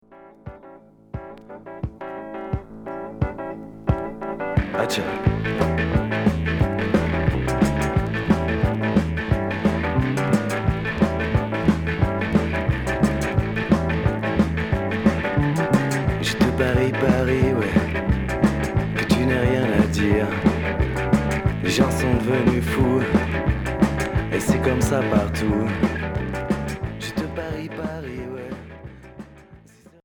Rock reggae